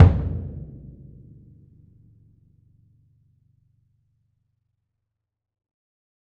BDrumNewhit_v7_rr1_Sum.wav